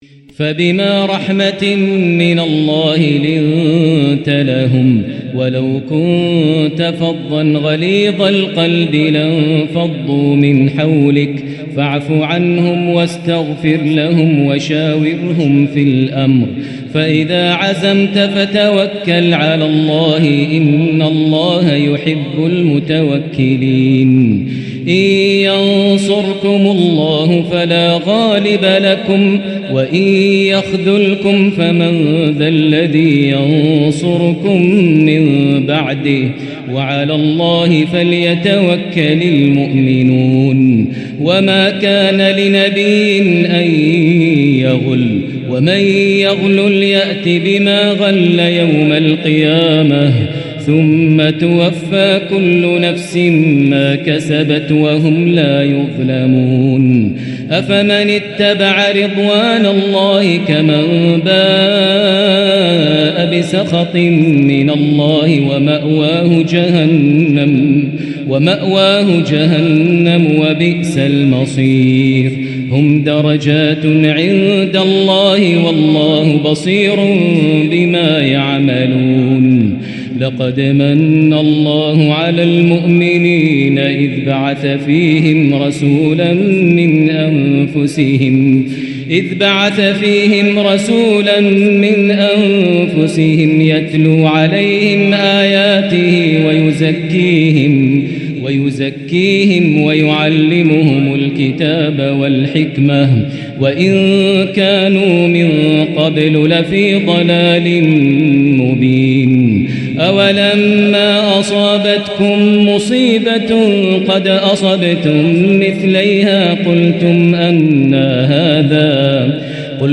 حلق بروحك وقلبك مع هذا الكُرد المُحبر الفريد من المتجدد الشيخ د. ماهر المعيقلي | ليلة ٥ رمضان ١٤٤٤هـ > مقتطفات من روائع التلاوات > مزامير الفرقان > المزيد - تلاوات الحرمين